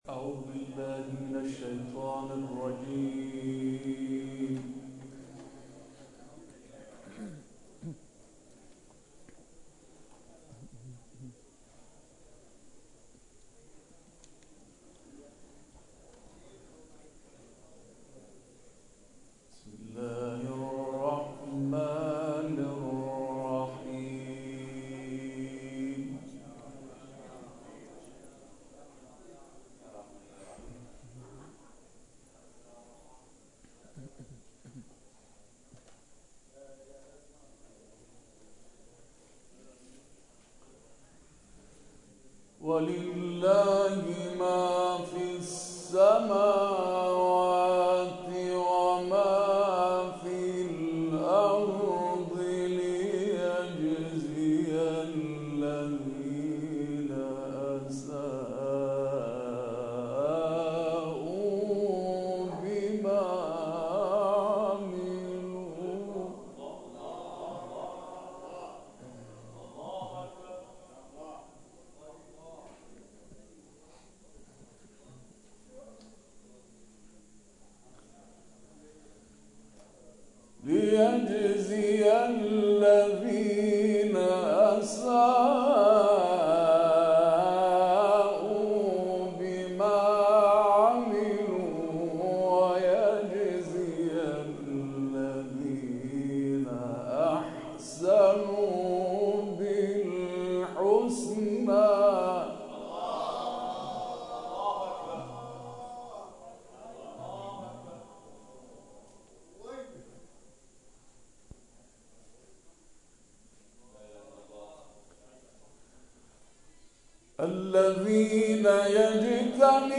جلسه ختم قرآن مسجد حاج‌نایب و جمع کثیری از قرآنیان + صوت و عکس
گروه جلسات و محافل: مراسم ختم قرآن کریم، یکی از سنت‌های حسنه‌ای است که موجب می‌شود، قرآنیان دیداری با یکدیگر تازه کنند و پای رحل قرآن به استماع تلاوت‌های یکدیگر بپردازند و از اساتید فقید خود یاد کنند.